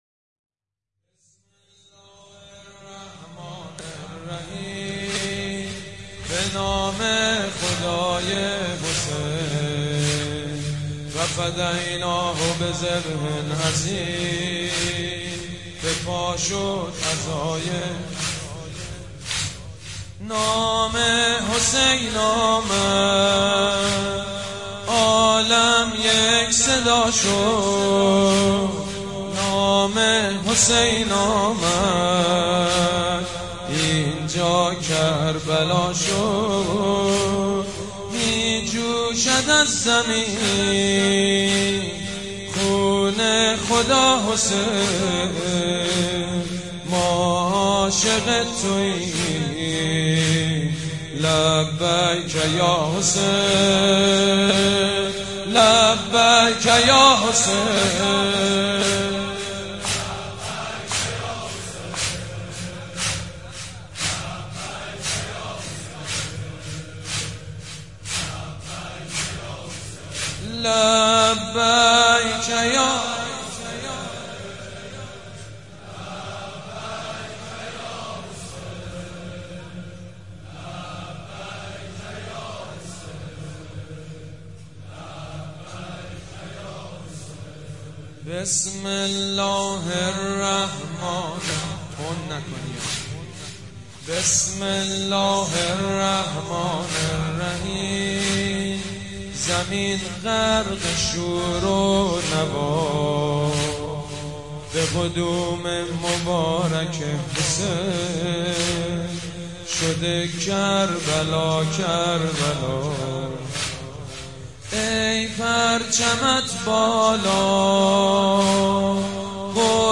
نوحه جديد
مداحی محرم